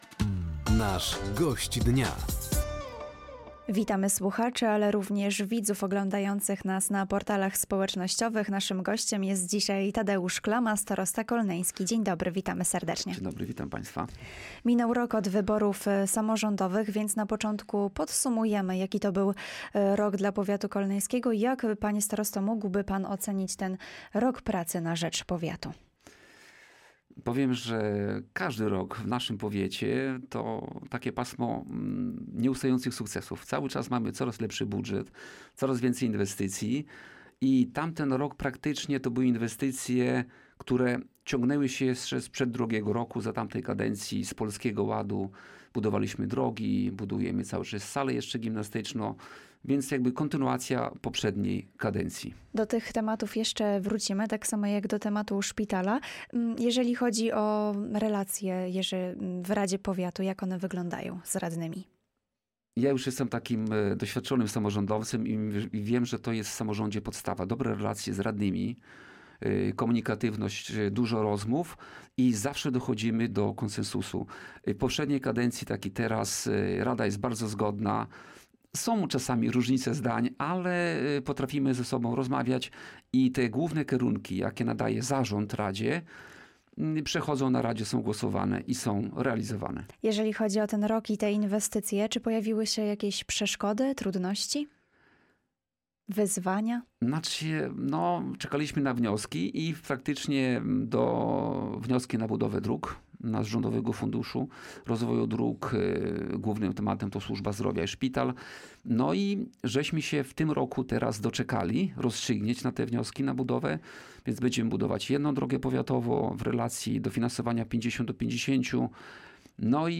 O kluczowych inwestycjach w powiecie kolneńskim, rozbudowie Szpitala Ogólnego w Kolnie, a także o wynikach I tury wyborów prezydenckich – mówił podczas audycji ,,Gość Dnia” starosta kolneński, Tadeusz Klama.